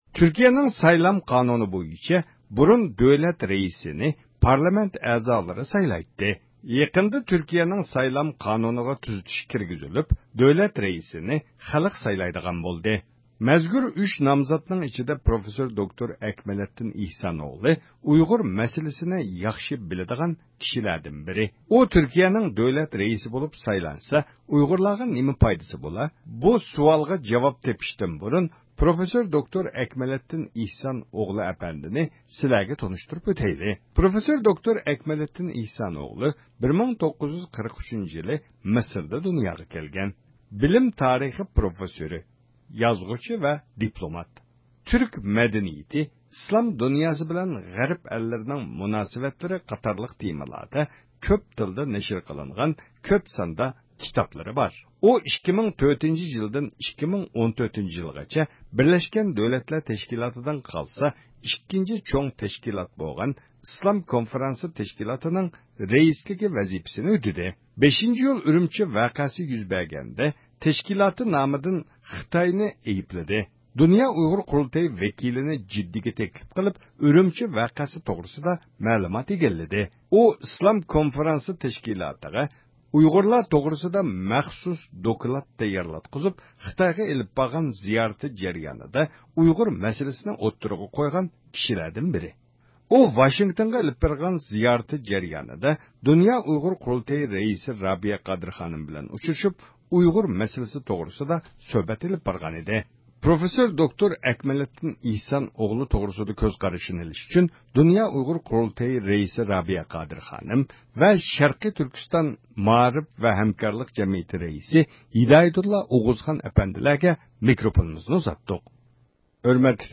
بۇ كىشى توغرىسىدىكى كۆز قارىشىنى ئېلىش ئۈچۈن شەرقىي تۈركىستان ئاممىۋى تەشكىلات مەسئۇللىرى بىلەن سۆھبەت ئېلىپ باردۇق.